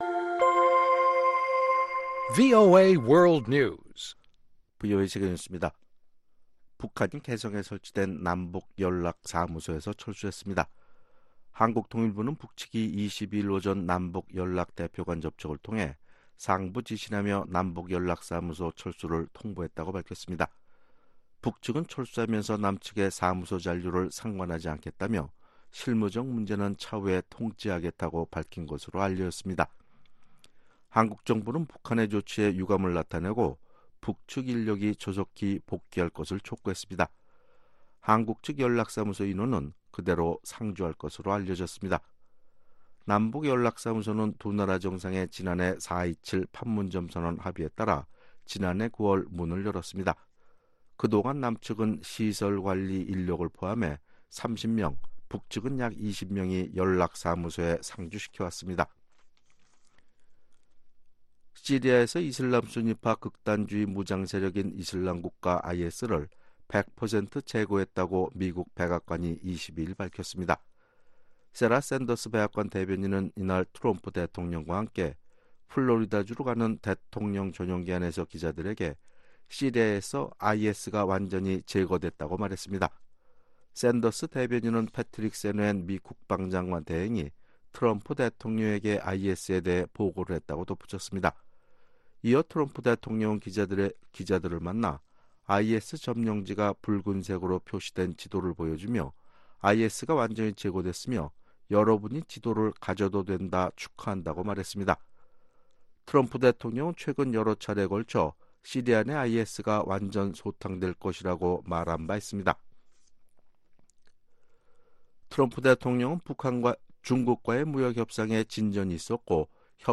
VOA 한국어 아침 뉴스 프로그램 '워싱턴 뉴스 광장' 2019년 3월 23일 방송입니다. 북한이 일방적으로 개성 남북공동연락사무소에서 철수했습니다. 존 볼튼 백악관 국가안보보좌관은 미국의 주된 목표는 북한의 비핵화이고, 하노이 정상회담에서 영어와 한글로 된 비핵화 정의를 김정은 북한국무위원장에게 전달했다고 말했습니다.